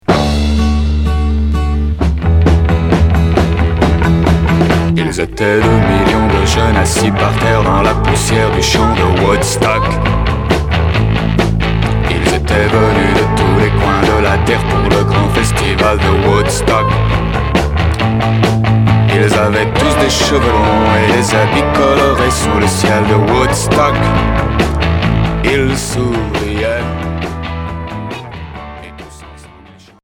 Rock pré- glam Unique 45t retour à l'accueil